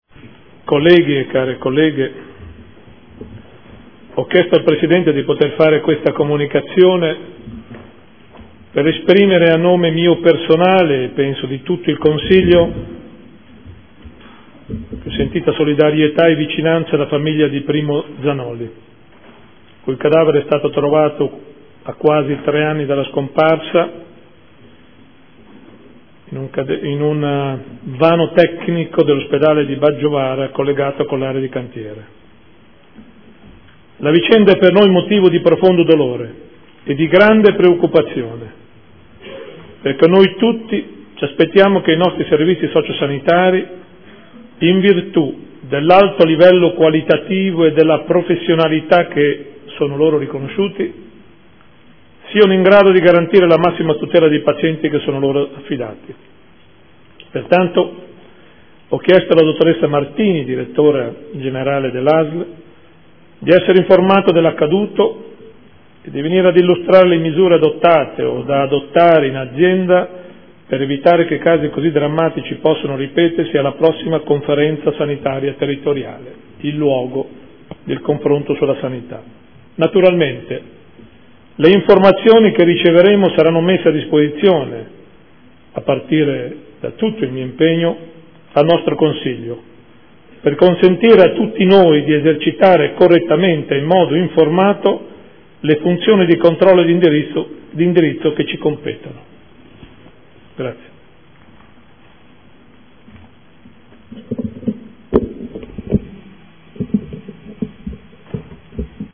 Gian Carlo Muzzarelli — Sito Audio Consiglio Comunale